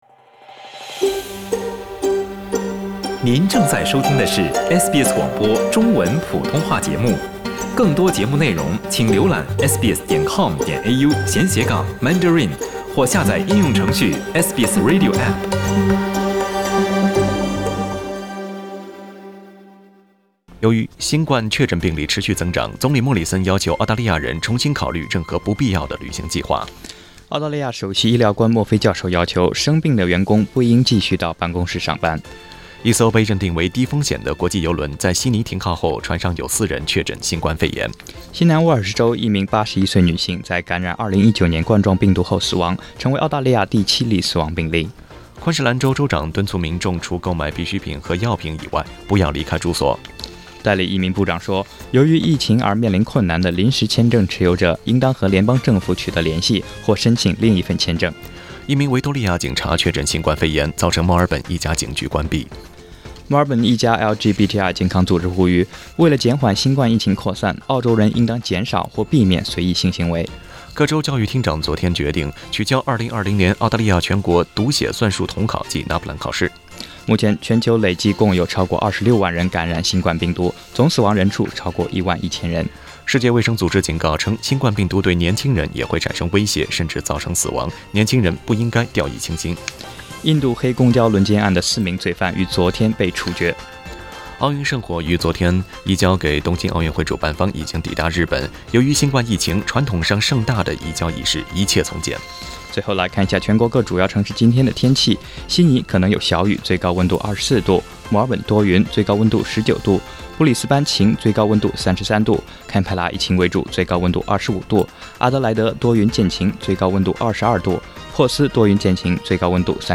SBS早新闻（3月21日）